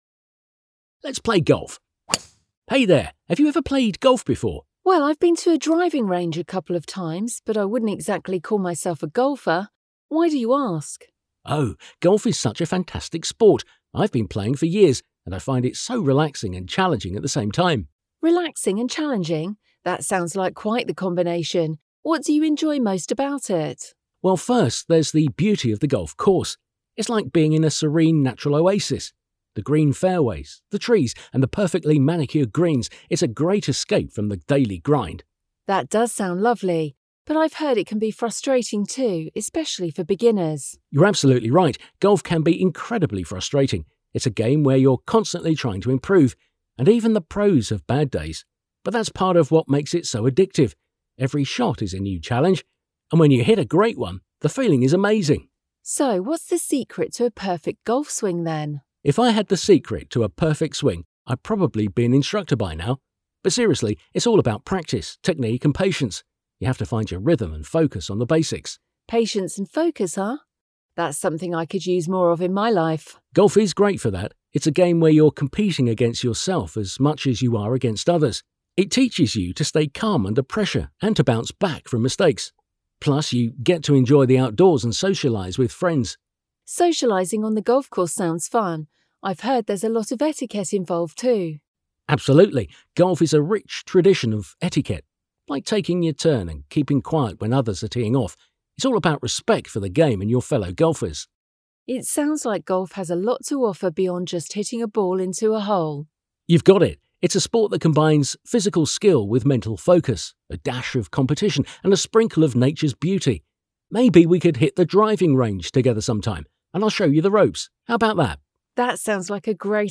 A párbeszédet a magazin 80. oldalán olvashatod.